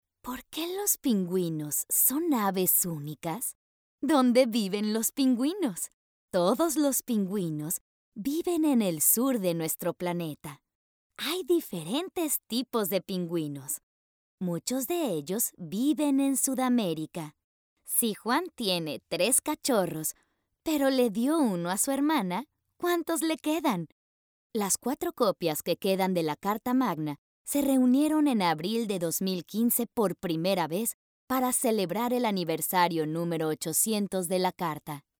e-learning k-12
Mexican